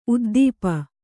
♪ uddīpa